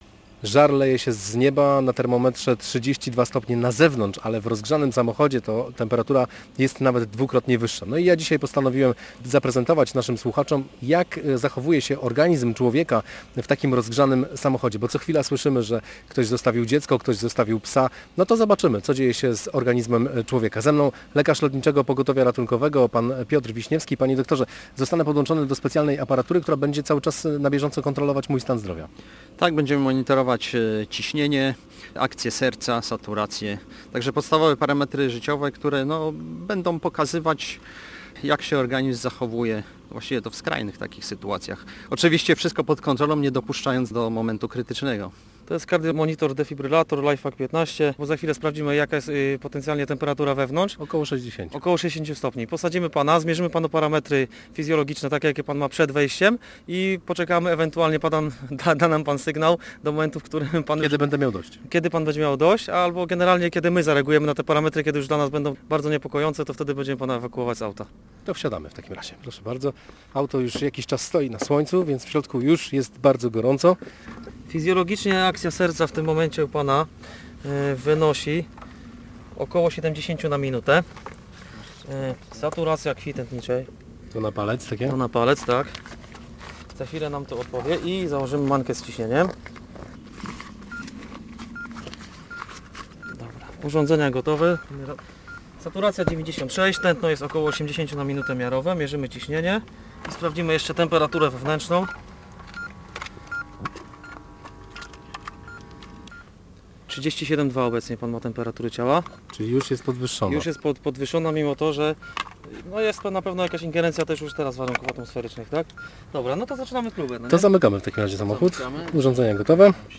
Temperatura ponad 60 stopni, a nasz reporter zamknięty w aucie.
Prosimy nie próbować tego na własną rękę, bo doświadczenie odbyło się pod okiem lekarza i ratownika medycznego z Lotniczego Pogotowia Ratunkowego w Gdańsku.